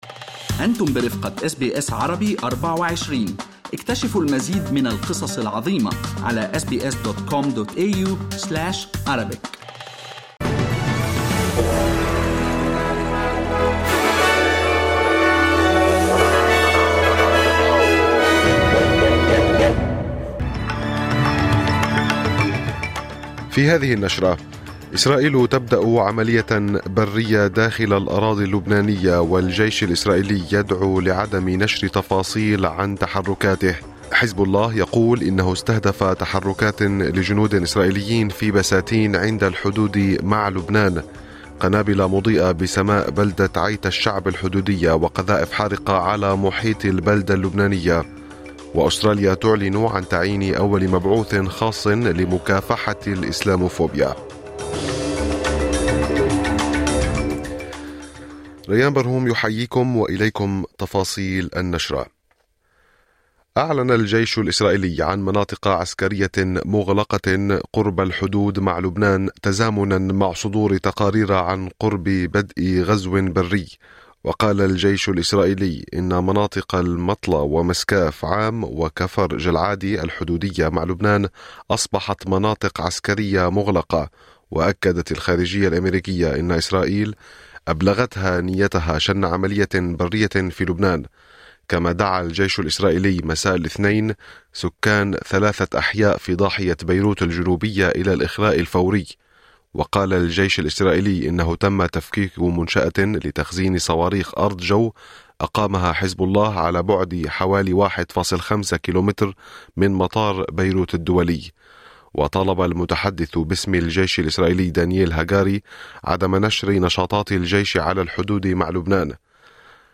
نشرة أخبار الصباح 01/10/2024